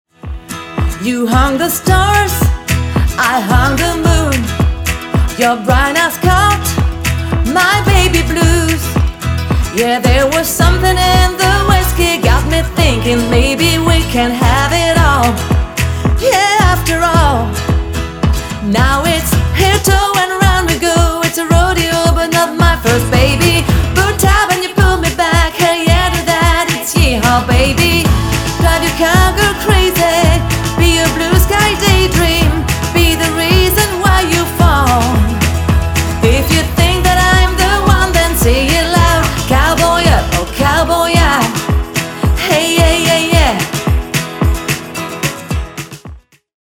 Tour de chant 100% country.